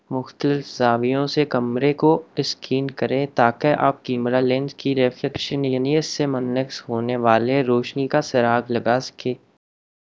Spoofed_TTS/Speaker_03/116.wav · CSALT/deepfake_detection_dataset_urdu at main